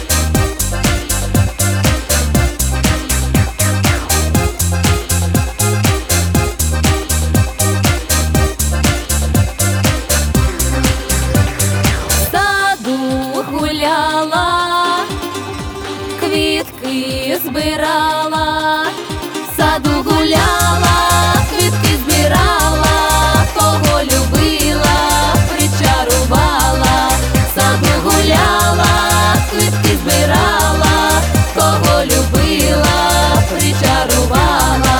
Жанр: Поп / Русские